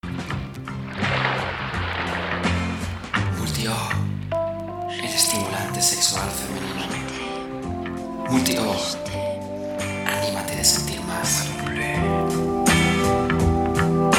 Young voice, capable to adapt to different styles and tones. Voz juvenil, capaz de adaptarse a diferentes estilos y tonos.
kastilisch
Sprechprobe: Sonstiges (Muttersprache):
estimulante-femenino-final-con-musica-2.mp3